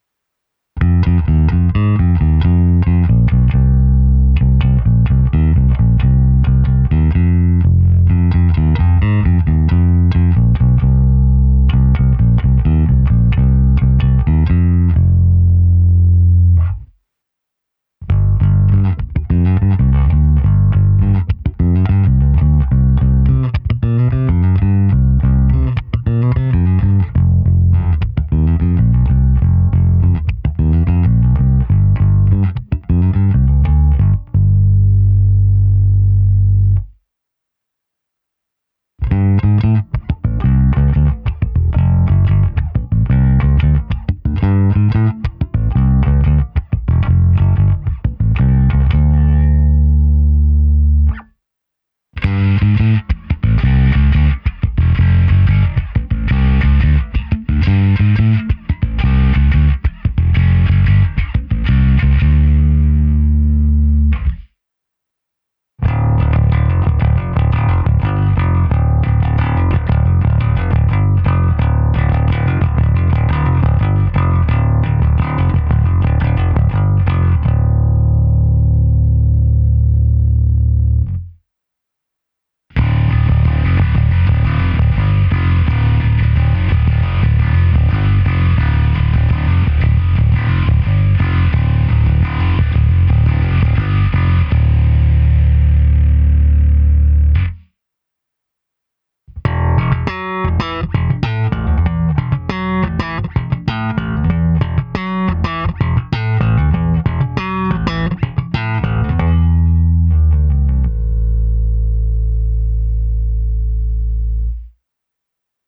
Nahrávka se simulací aparátu, kde bylo použito i zkreslení, v jedné dvojukázce jsem použil podladění do D a hru na takto podladěné struně, a to navíc trsátkem, a na konci je ještě hra slapem